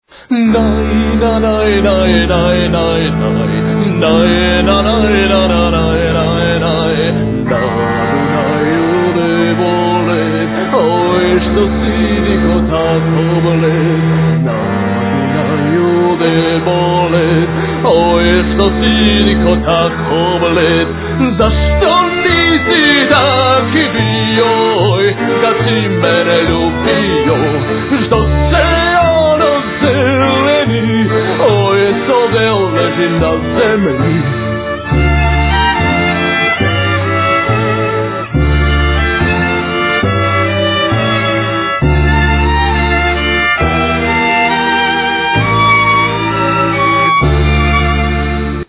Klarinet, Alt saxophone
Accordion
Vocals
Guitar, Balalaika
Violin